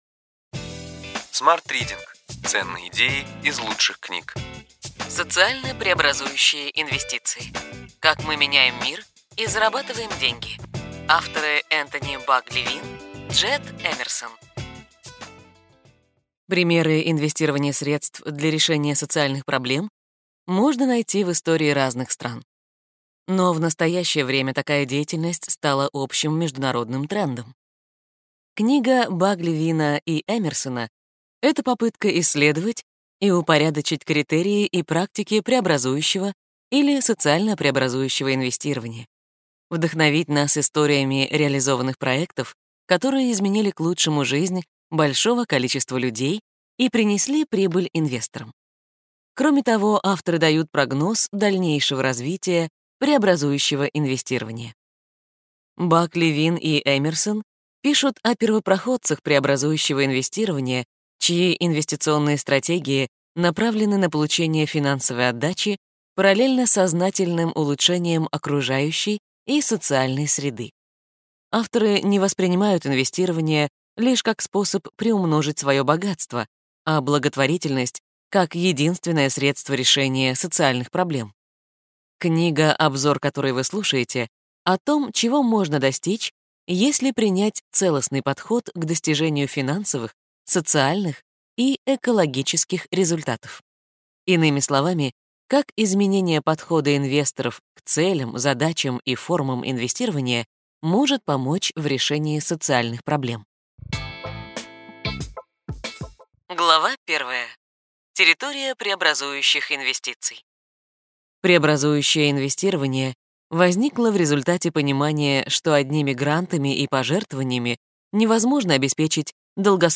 Аудиокнига Ключевые идеи книги: Социально-преобразующие инвестиции.